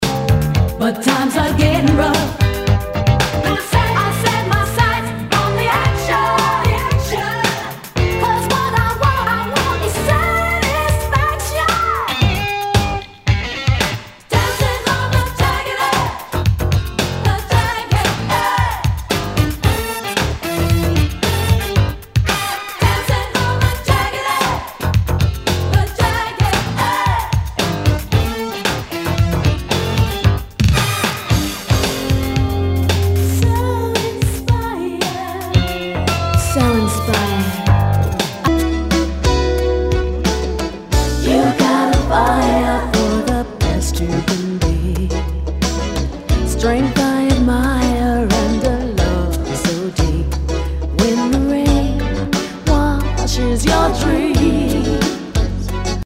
SOUL/FUNK/DISCO
ナイス！ダンス・クラシック！